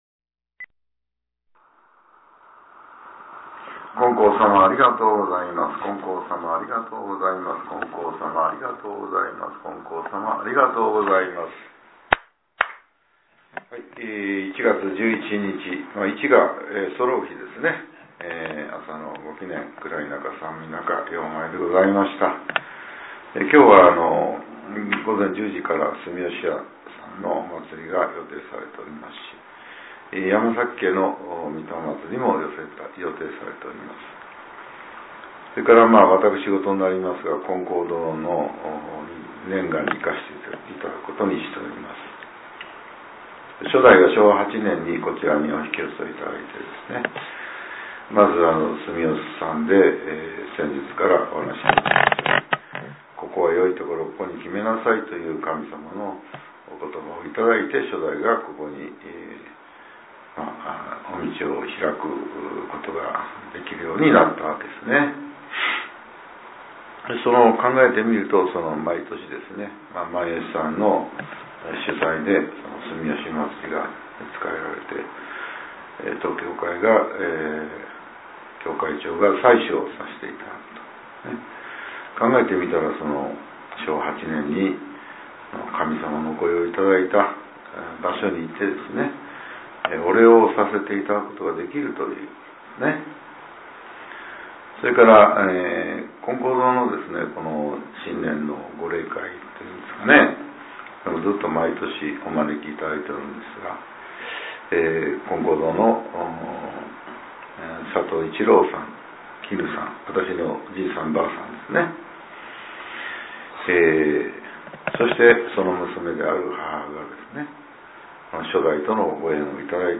令和８年１月１１日（朝）のお話が、音声ブログとして更新させれています。 きょうは、前教会長による「教祖さまの道」です。